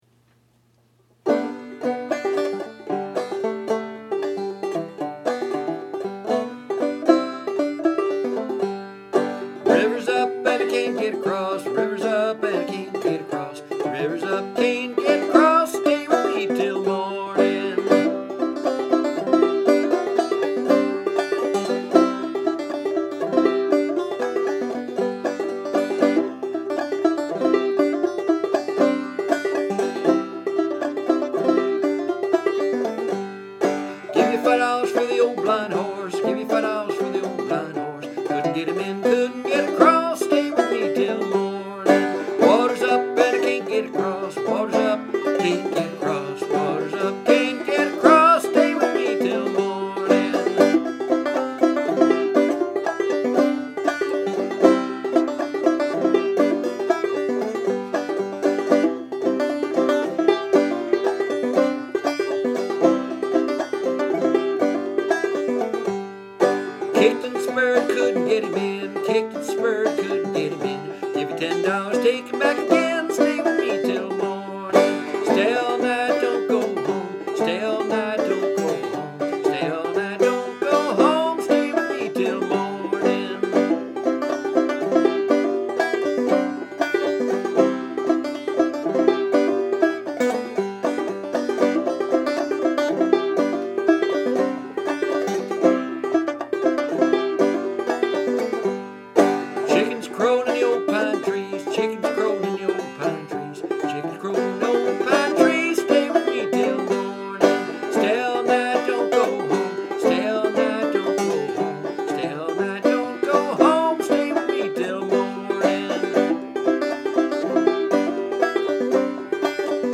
My rendition is adapted from the recordings of Tommy Jarrell, Fred Cockerham, and Oscar Jenkins from tracks recorded in the 1960s and early 1970s when these fellows were pretty darn old. The cut “Stay All Night” refers to a flood that may cause house guests to stay a little longer.